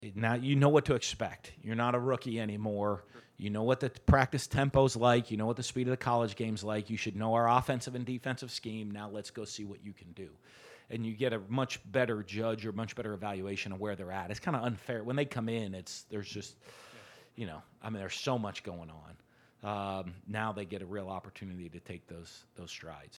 At the spring football press conference, Dan Mullen was sure to emphasize that now is the time for second-year players to step up to the plate.